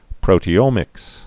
(prōtē-ōmĭks)